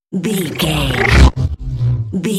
Sci fi alien energy pass by
Sound Effects
futuristic
intense
pass by